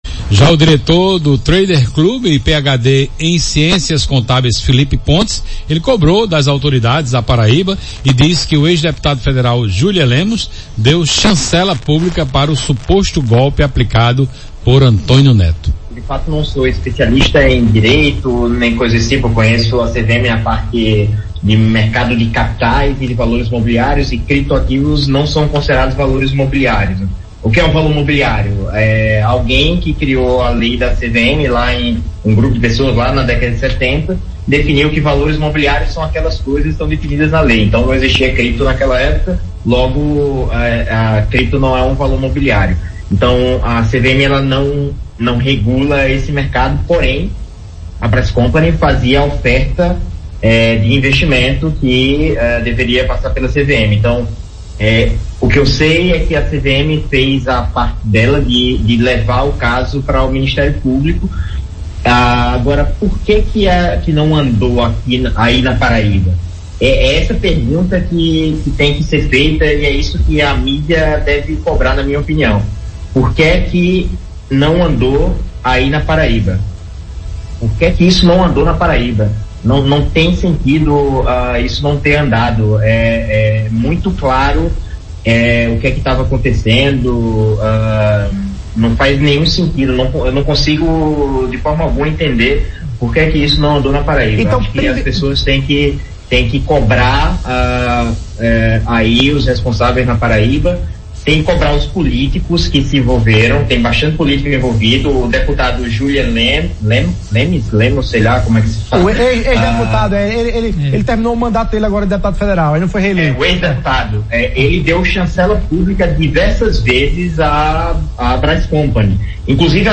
“Ele deu chancela pública diversas vezes à BraisCompany. Inclusive agora, dia 15 de janeiro, quando o esquema já estava desmoronando ele foi lá e postou no Instagram dele que todo o negócio passa por tempestades e bla bla bla, todo aquele papo de coach motivacional que todo mundo já sabe onde é que termina”, disse em entrevista nessa segunda-feira (6) ao programa ‘Frente a Frente’, da TV Arapuan.